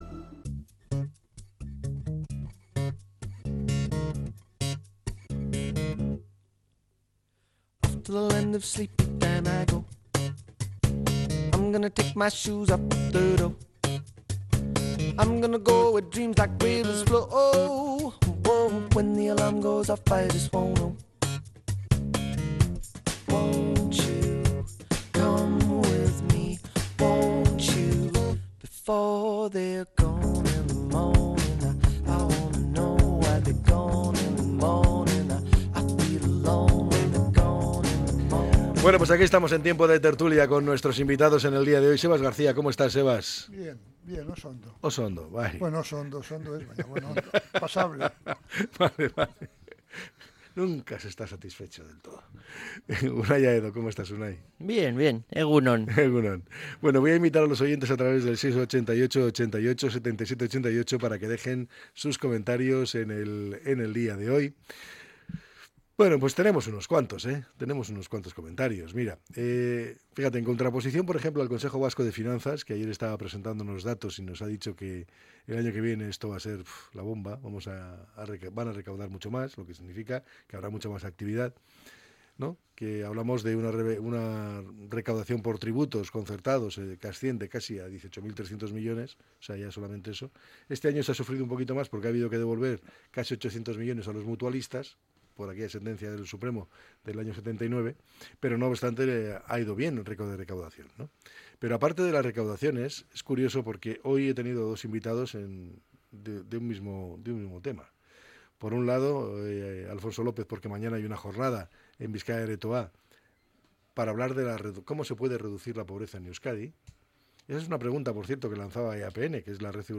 La Tertulia 16-10-24.